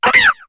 Cri de Furaiglon dans Pokémon HOME.